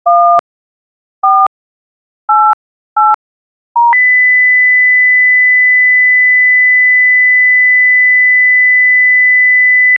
ifre-dorulanma-sesi-kaj5bqsd.wav